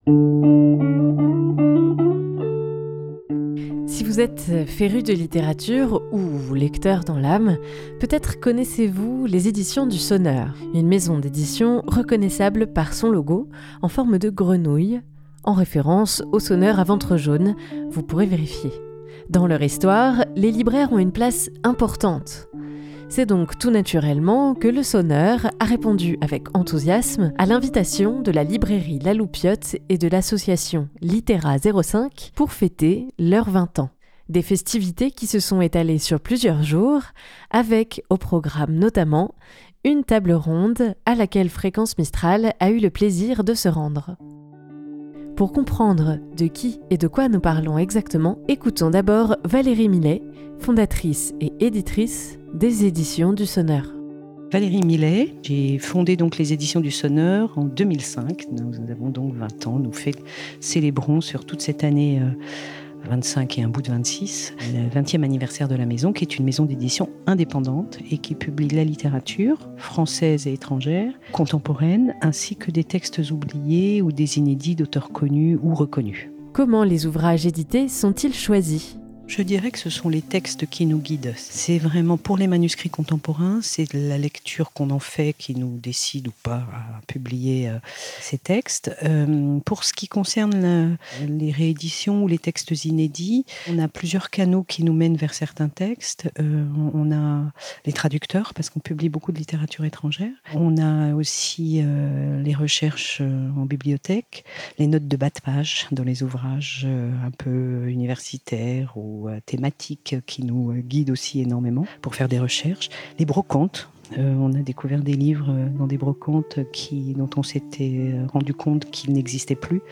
Parmi les temps forts proposés, une table ronde accueillie au sein de La Cinémathèque d'Image de Montagnes , à laquelle Fréquence Mistral a eu le plaisir d'assister.